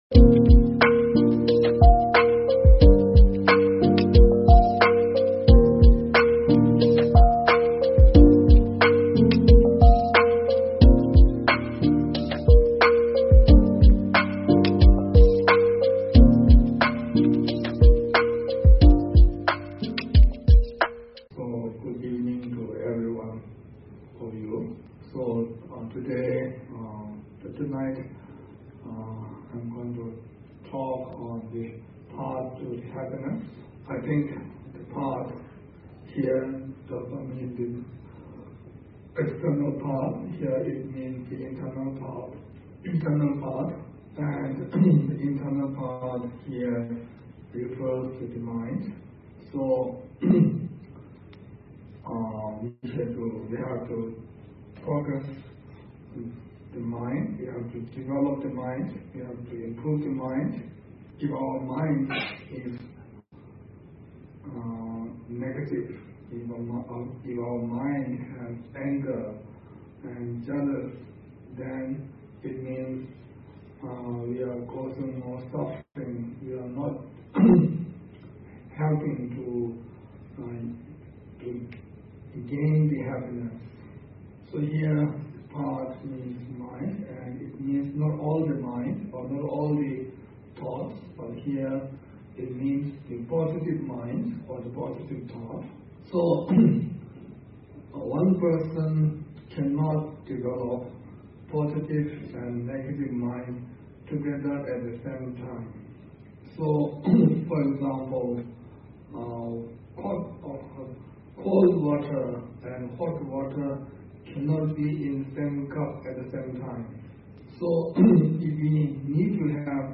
14.The Path to Happiness_H.H. the 42nd Sakya Trizin's Dharma Teaching Given in 2023_The Sakya Tradition
Title: The Path to Happiness Author: H.H. the 42nd Sakya Trizin Venue: Lima, Peru Audio Video Source: Sangha Activa Peru Lima Youtube